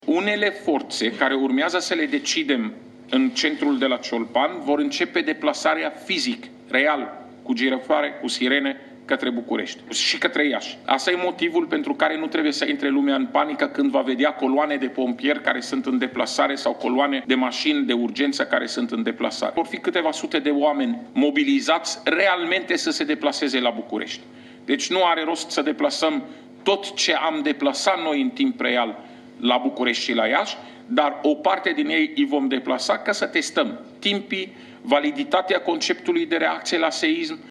La rândul său, secretarul de stat în MAI, Raed Arafat, a spus că a fost ridicat nivelul de alerta la roşu, valabil pentru toate autorităţile care pot interveni în astfel de cazuri: